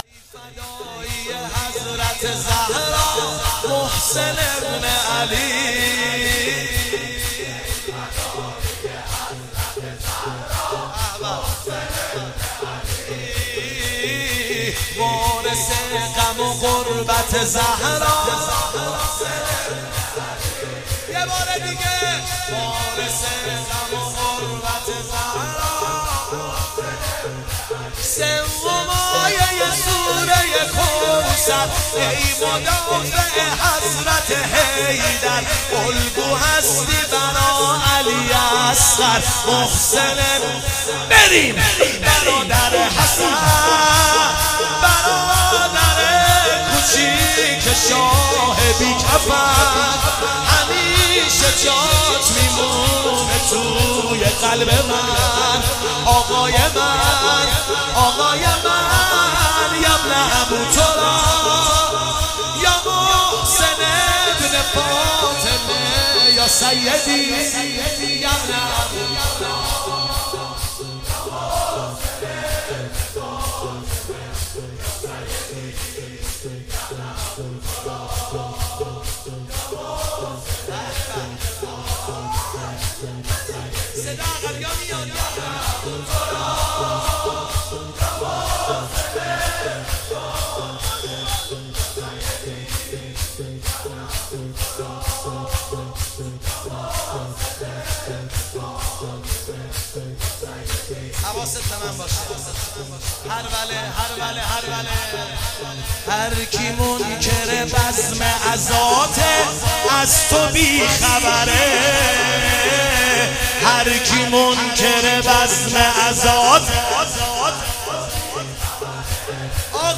محسنیه 97 - شور - ای فداییه حضرت زهرا محسن ابن علی